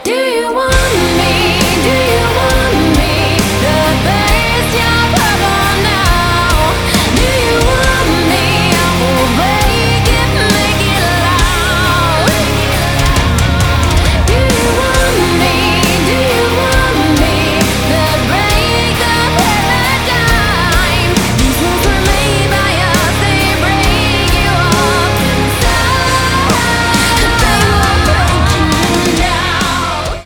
• Качество: 192, Stereo
женскому вокалу